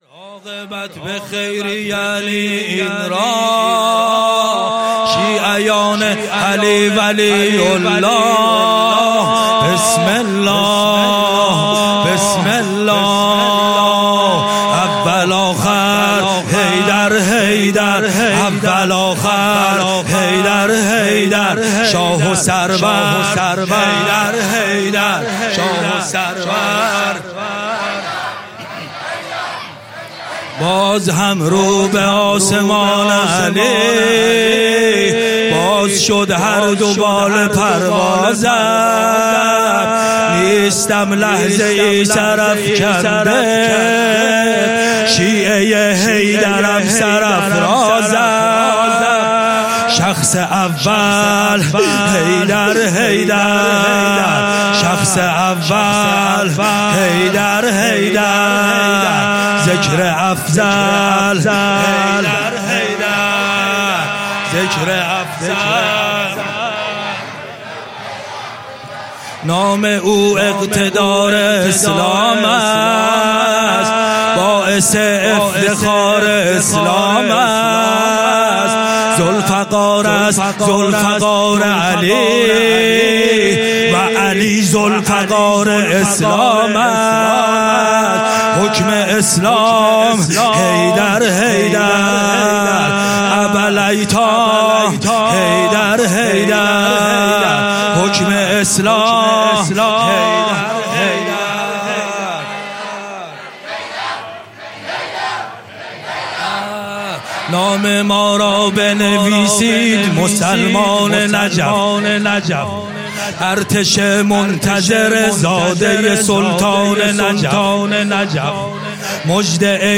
هیئت محبان الحسین علیه السلام مسگرآباد
مراسم بیعت با ولایت فاتح قلعه خیبر ، حضرت امیرالمومنین علی ابن ابی طالب علیه السلام سـال ۱۴۰۴